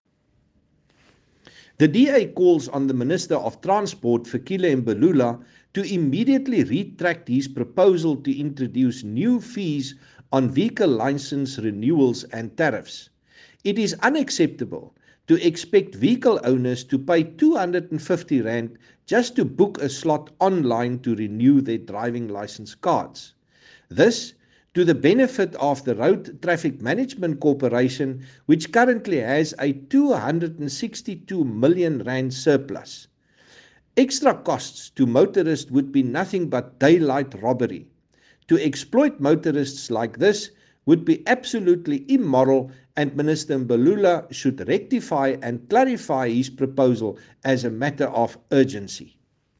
Afrikaans soundbites by Chris Hunsinger MP.